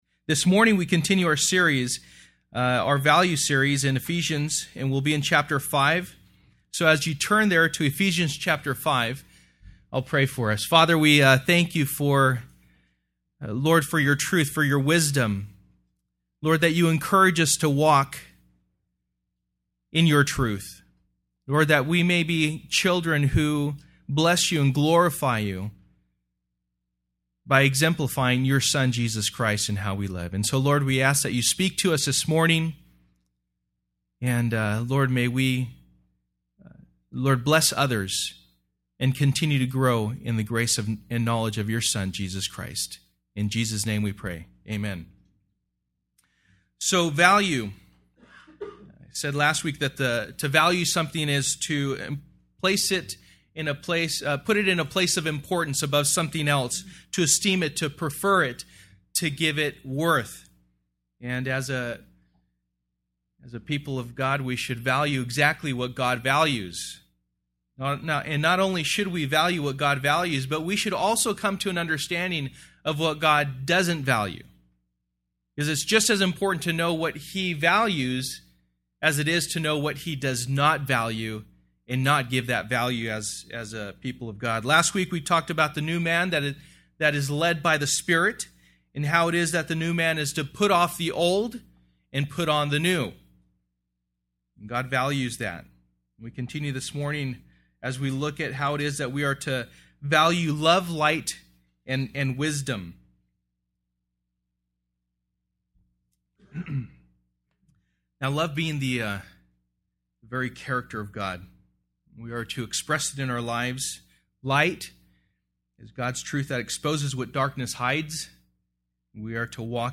Ephesians 5:1-21 Service: Sunday Morning %todo_render% « Value